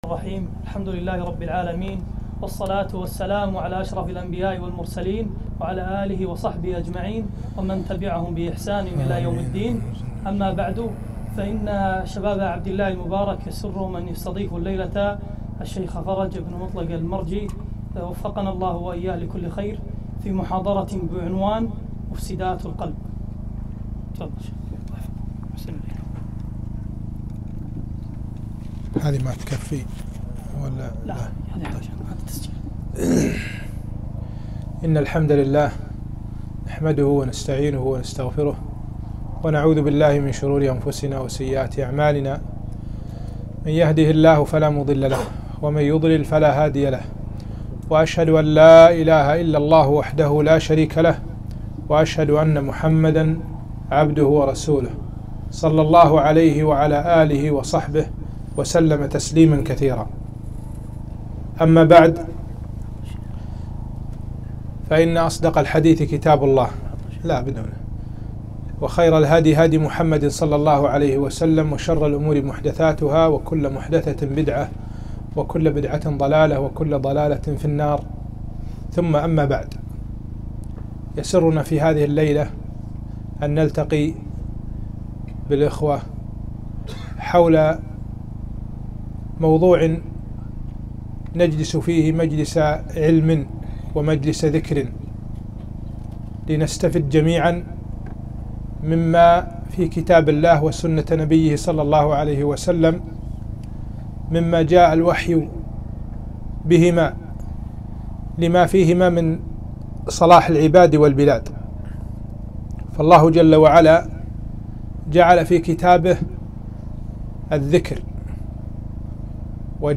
محاضرة - مفسدات القلب